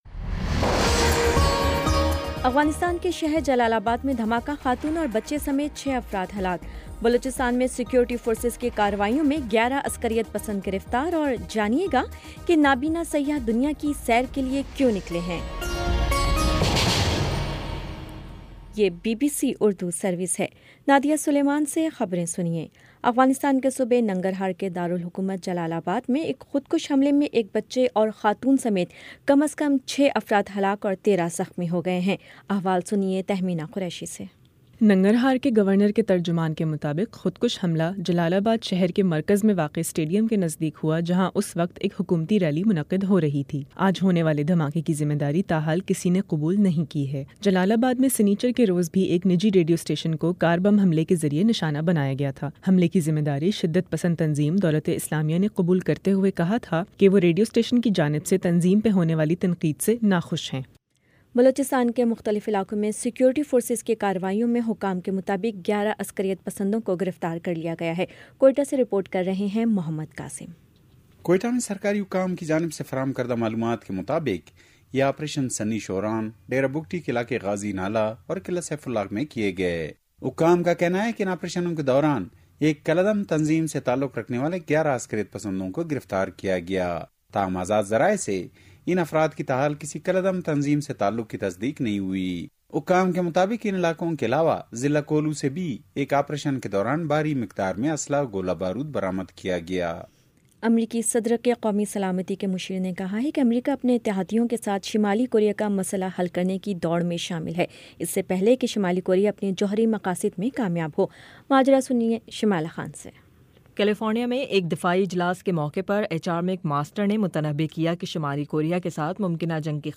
دسمبر 03 : شام چھ بجے کا نیوز بُلیٹن